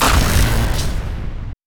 SUTeslaStorm_expc.wav